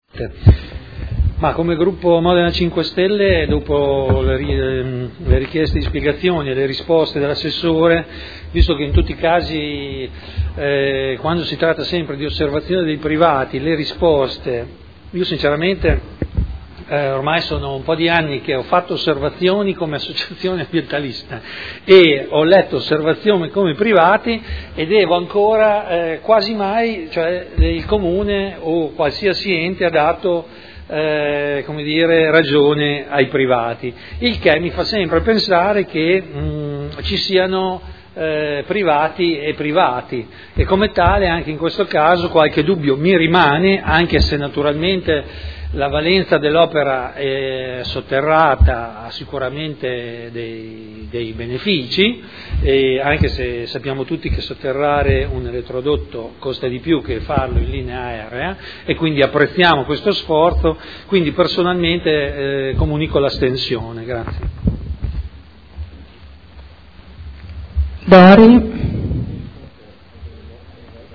Seduta del 18/06/2012. Dichiarazione di voto su proposta di deliberazione.